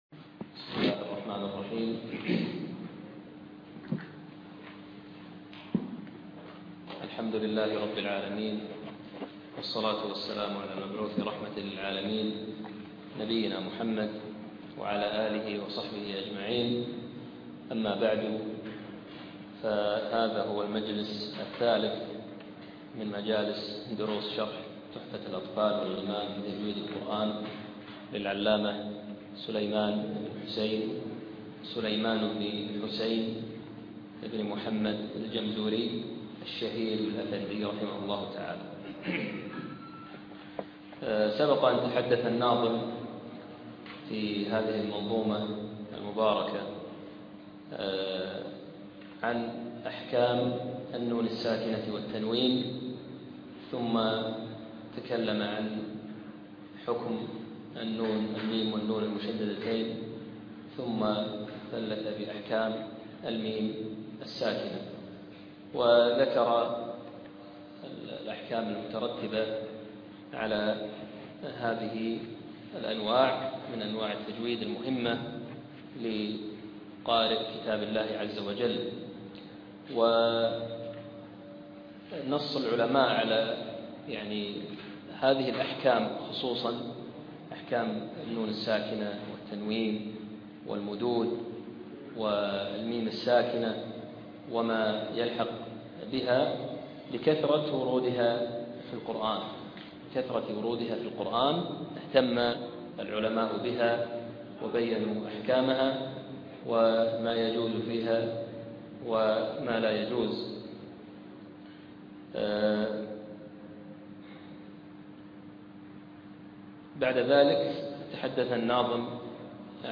أقيمت الدورة شهر 8 عام 2014 في مسجد الحمادي في منطقة المهبولة بدولة الكويت
الدرس الثالث والأخير